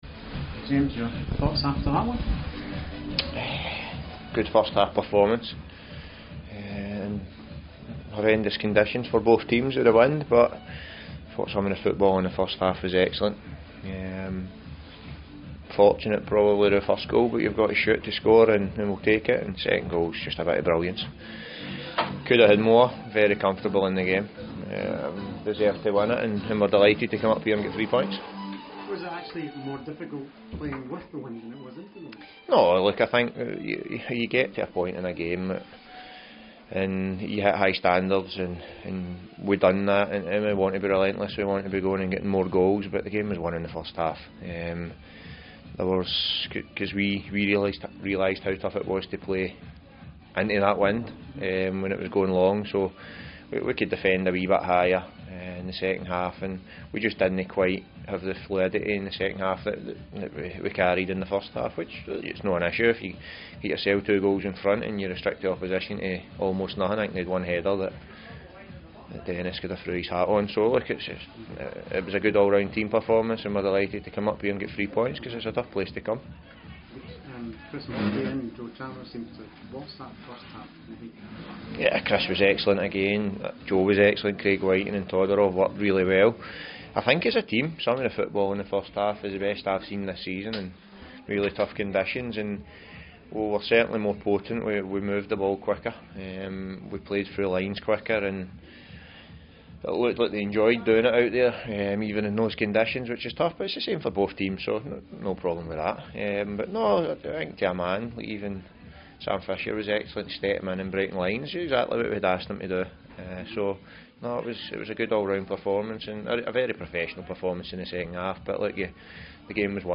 post match comments
interview